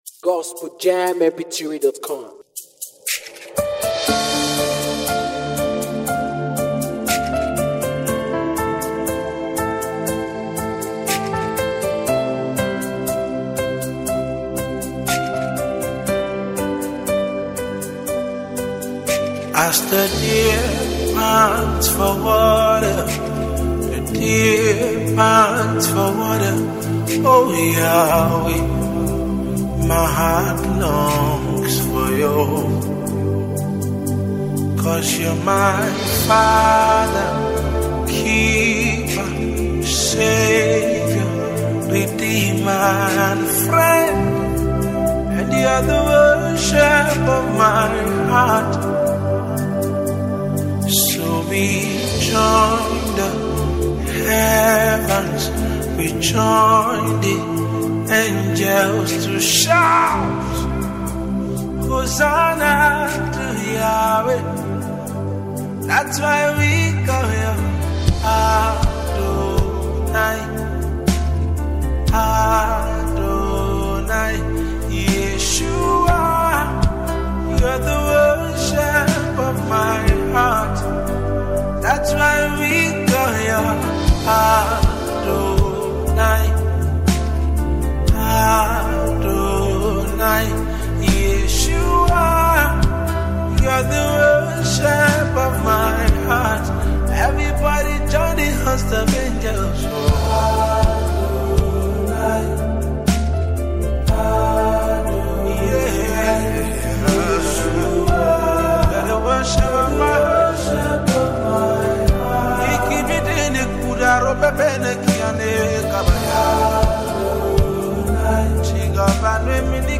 global song